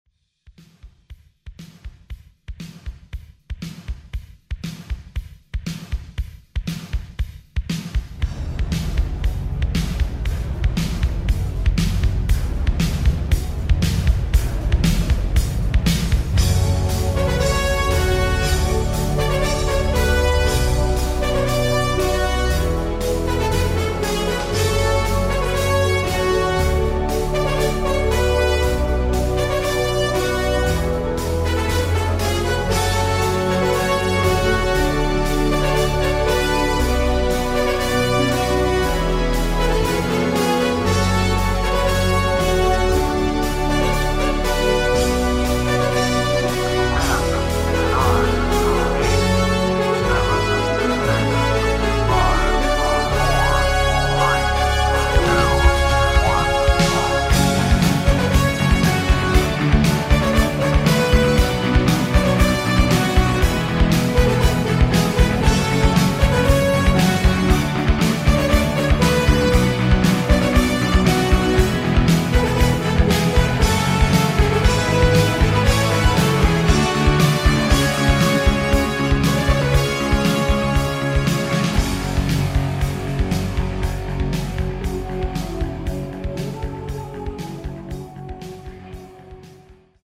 Genre: HIPHOP Version: Clean BPM: 72 Time